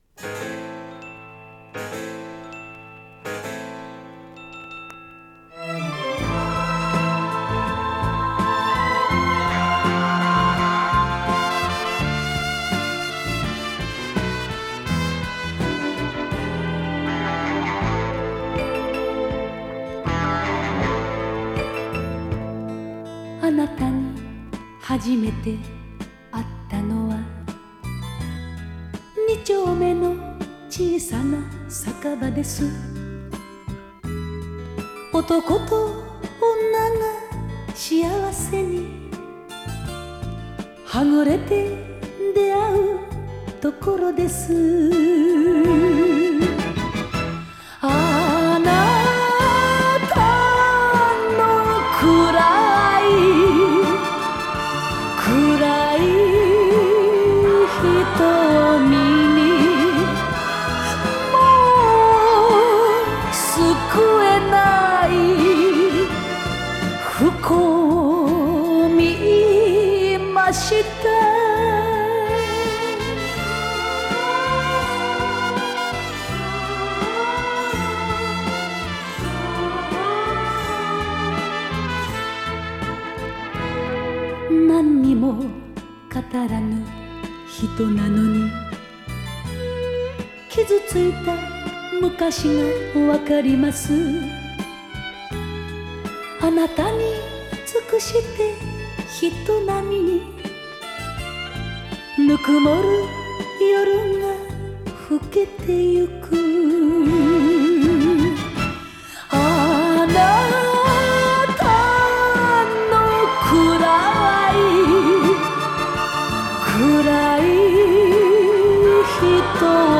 Жанр: Enka